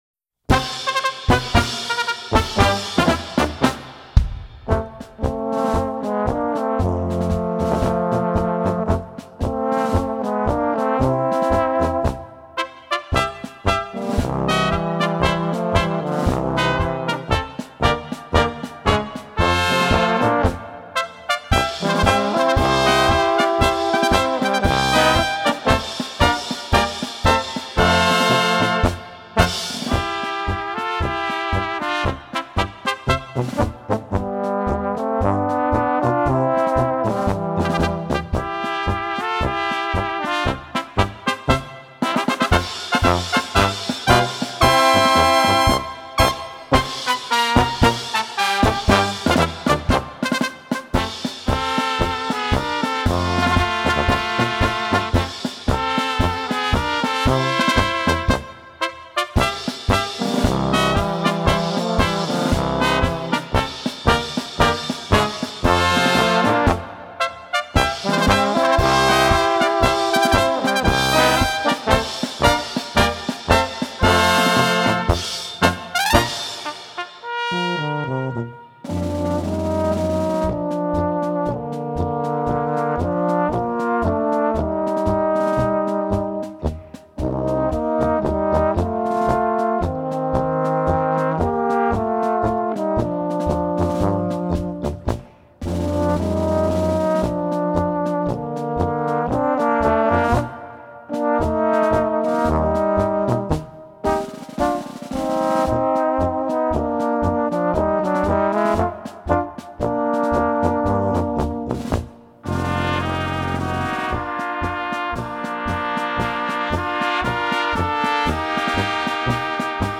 Gattung: Polka für Böhmische Besetzung
Besetzung: Ensemblemusik Blechbläserensemble